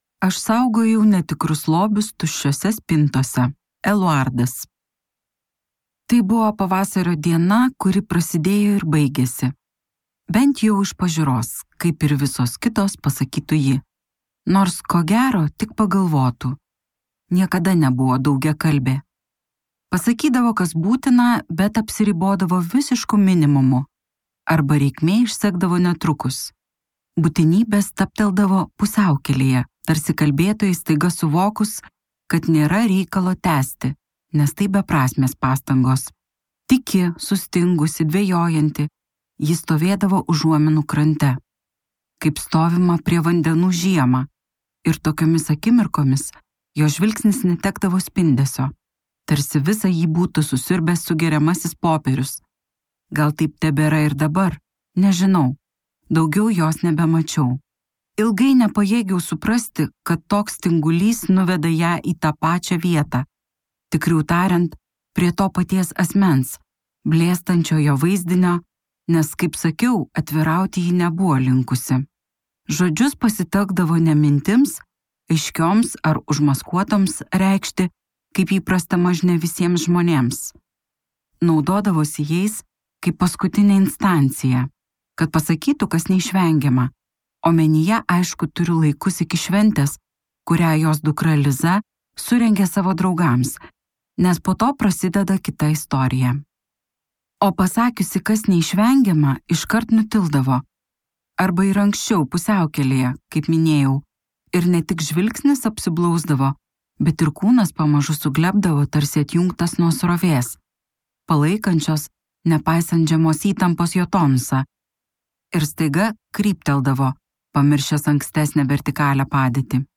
Maria Judite de Carvalho audioknyga „Tuščios spintos“ – tai įtaigus portugalų romanas, nagrinėjantis vienatvės, vidinės tylos ir moterų padėties patriarchalinėje visuomenėje temas.